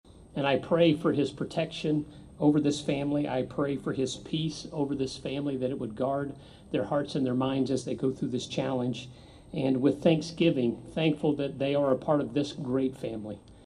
A community gathering was held outside Anderson Hall Tuesday evening as state, local and faith leaders came together in support of Kansas State University President Richard Linton, who earlier in the day announced his diagnosis of throat and tongue cancer.